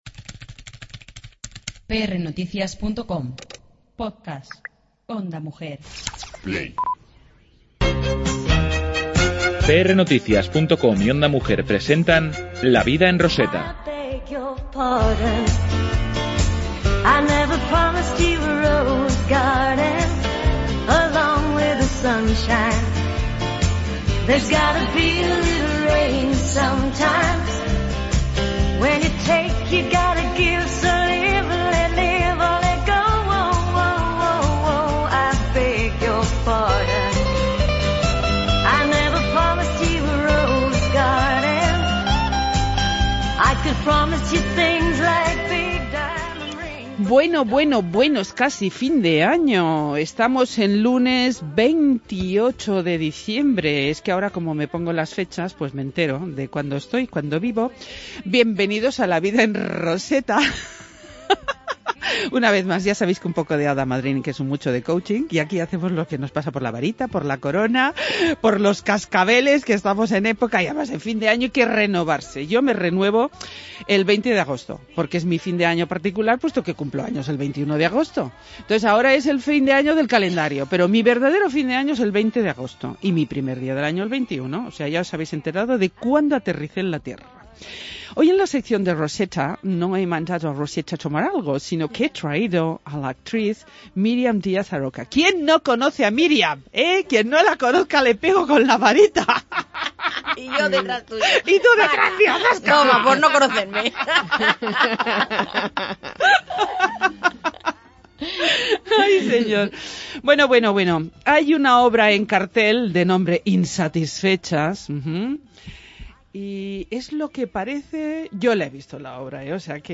La obra Insatisfechas teniendo un éxito atronador y por ello, hoy hemos contado en los estudios de Ondamujer con la actriz y showbusiness Miriam Diaz-Aroca.